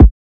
Kick (Wild).wav